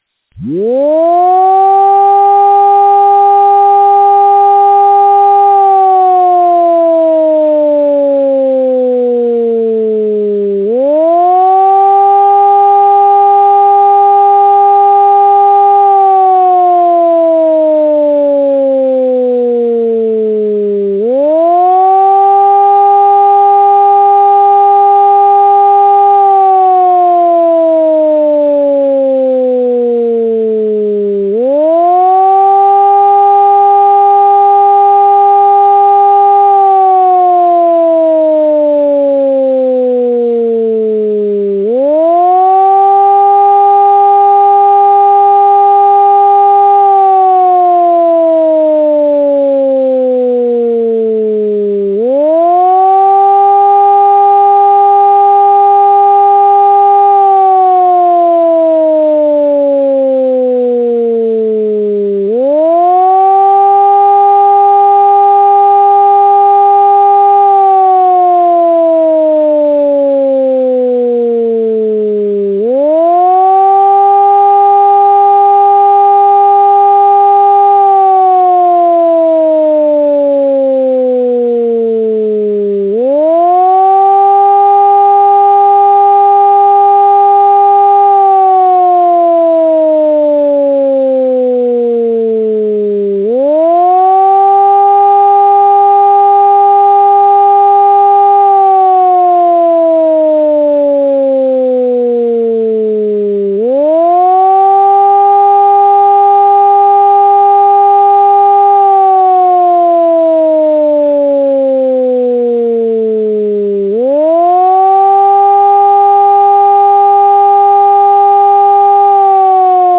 Sygnały alarmowe i komunikaty ostrzegawcze
Sygnał ogłoszenia alarmu
ogloszenie_alarmu.mp3